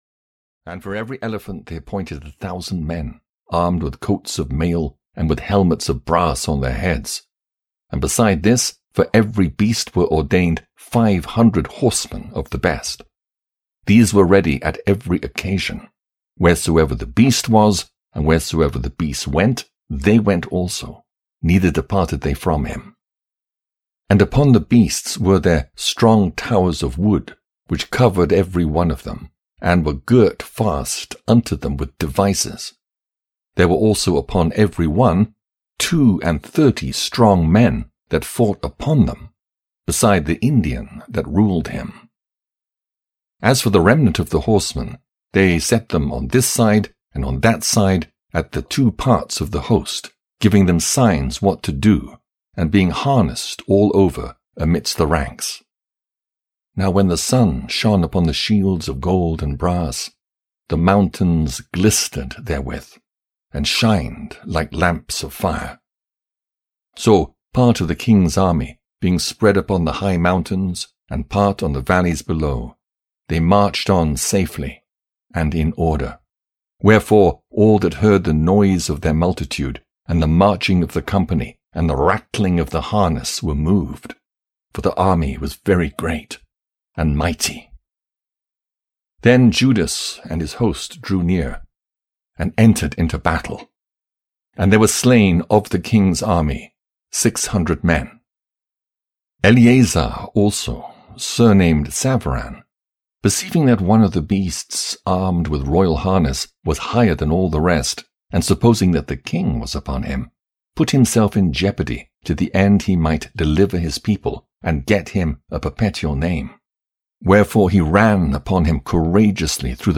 Audio kniha1st and 2nd Book of Maccabees (EN)
Ukázka z knihy